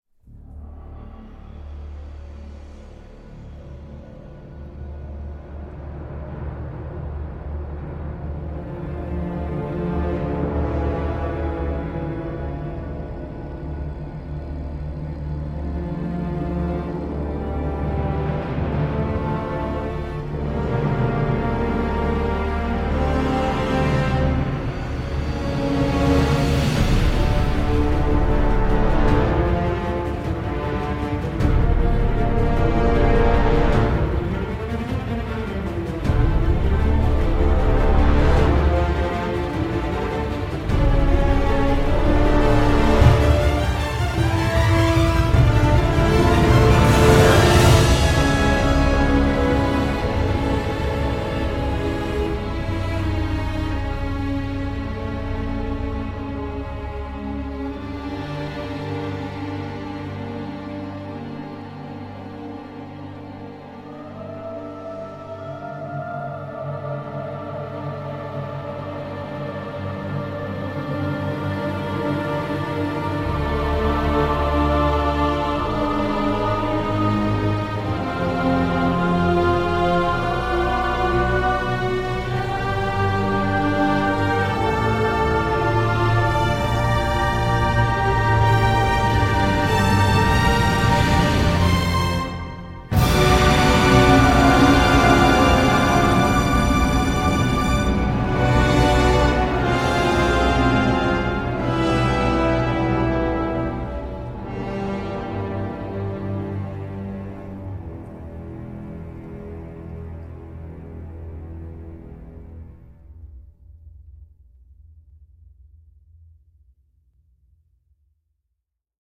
Thèmes larges, orchestrations classieuses, synthés…